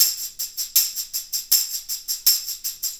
Tambourine 01.wav